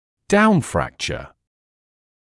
[‘daunˌfrækʧə][‘даунˌфрэкчэ]направленный вним отлом соответствующего сегмента челюсти при остеотомии